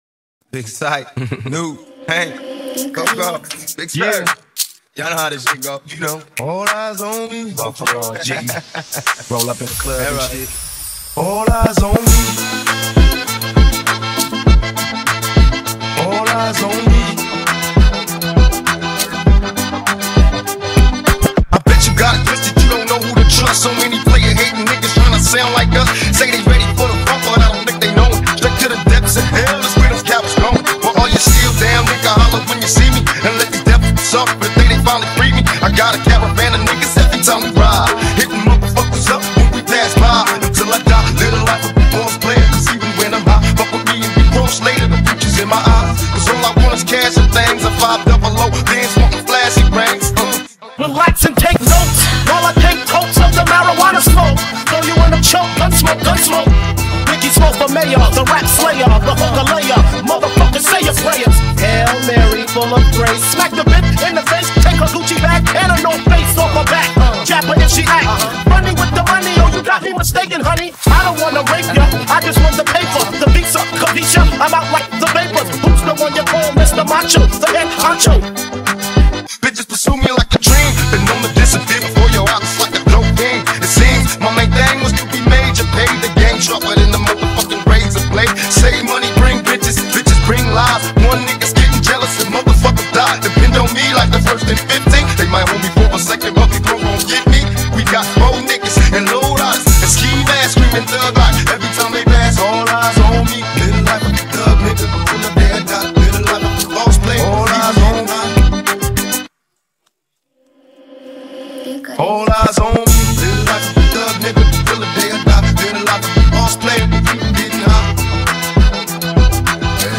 Кавказ лезгинка ремикс